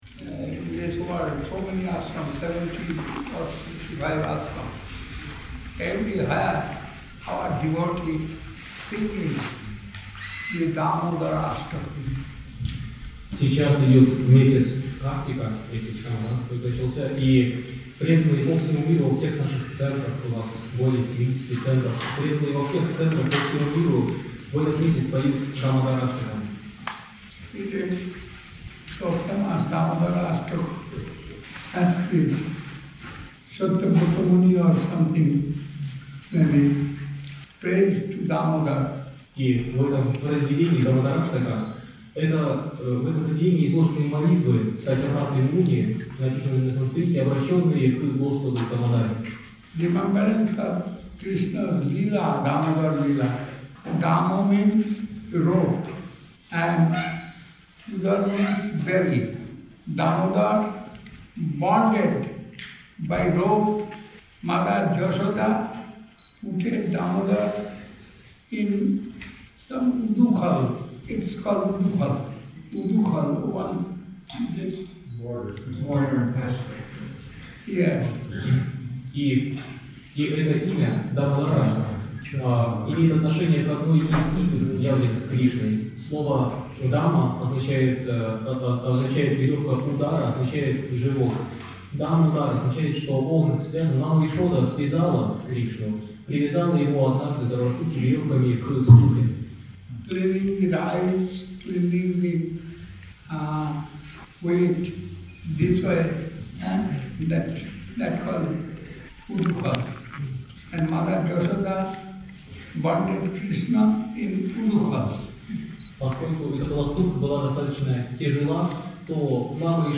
Об игре Господа Дамодара. Бхаджан "Намам Ишварам" и "Радхе Джайа Джайа".
Place: Sri Chaitanya Saraswat Math Saint-Petersburg